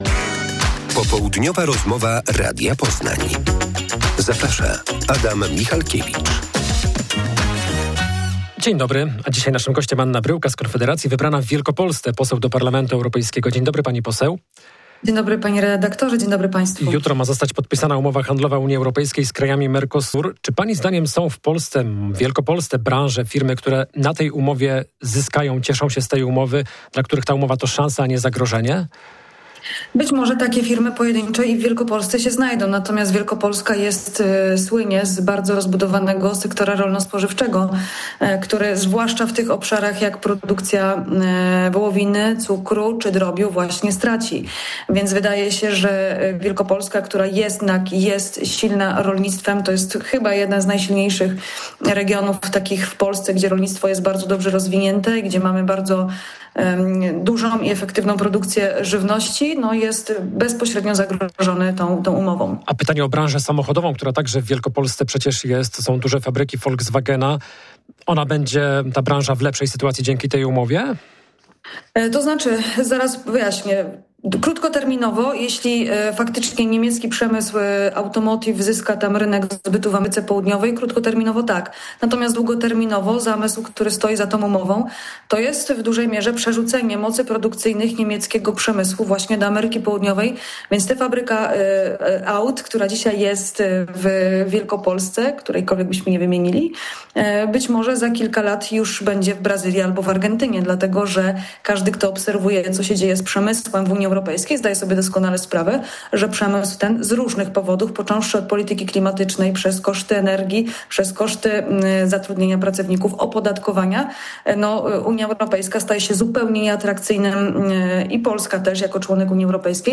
Rozmowa z poseł Anną Bryłką o umowie Mercosur.